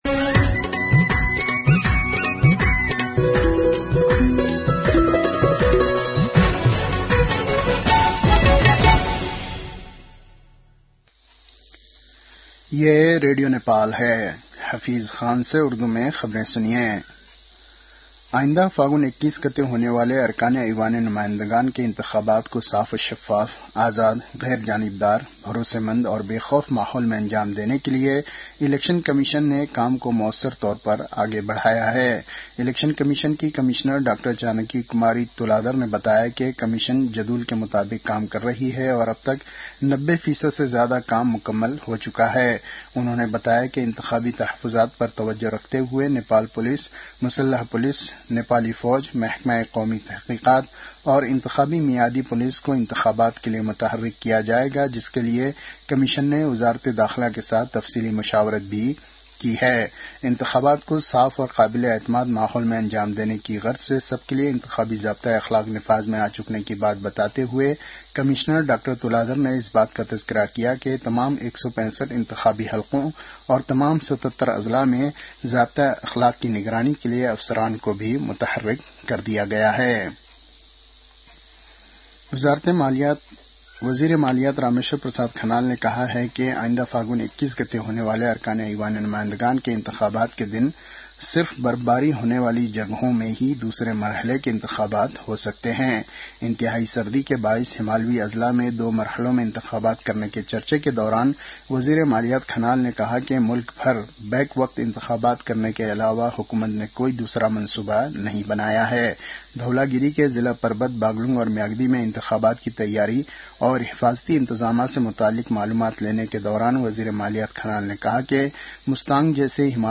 उर्दु भाषामा समाचार : २२ माघ , २०८२